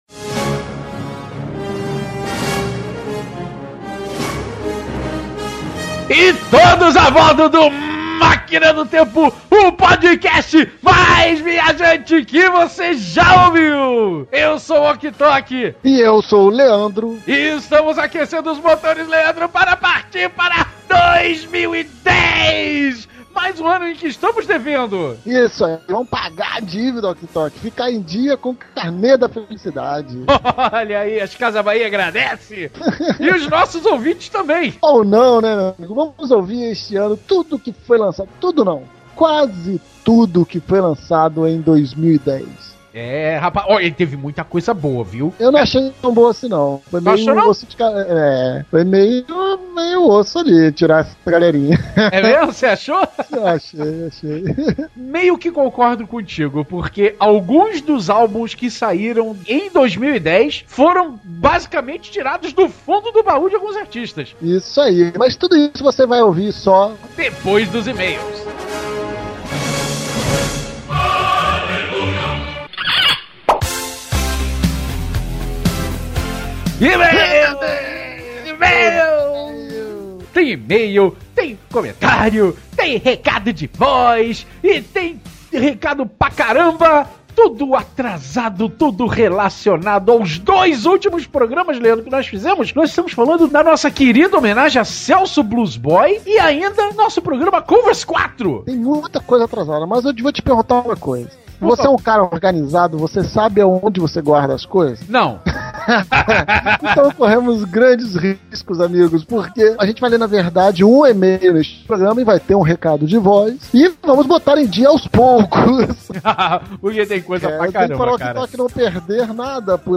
Quanto tempo tem esta edição? 97 minutos de rock n’ roll, heavy metal, alternativo e muito mais.